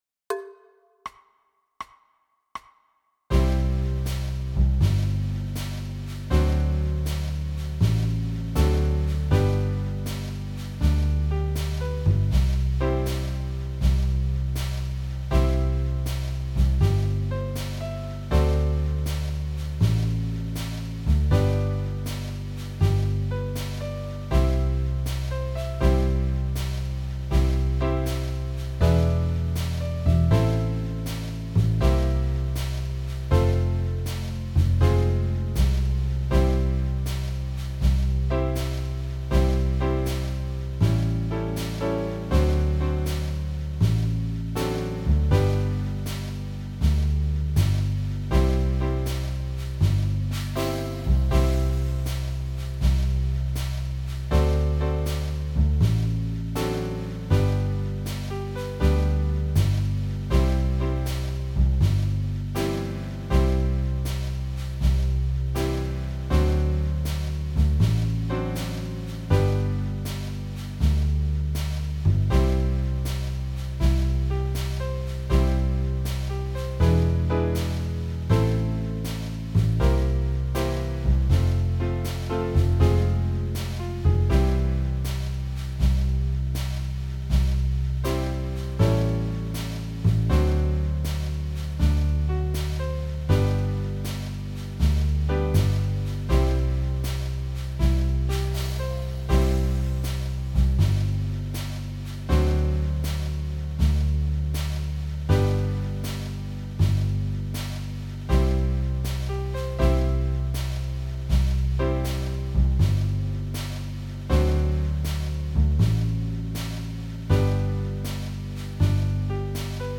Play-A-Longs